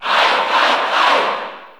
Ike_Cheer_Italian_SSB4_SSBU.ogg